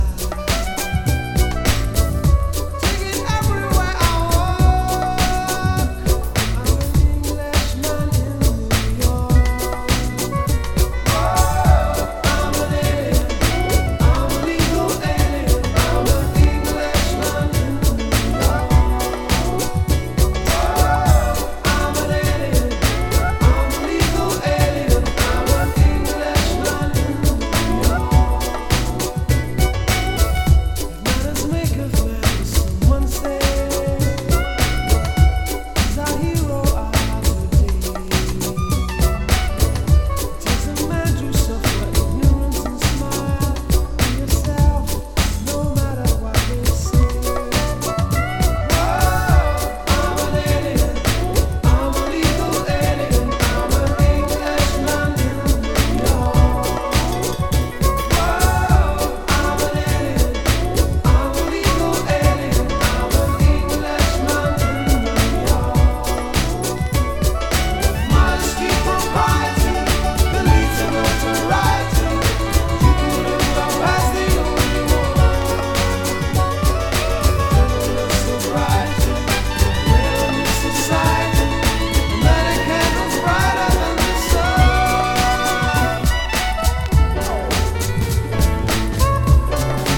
太めのボトムに入れ替わったPt.1に新たなビートに差し替えられたPt.2、どちらもフロア仕様で◎。
POPS# BREAK BEATS / BIG BEAT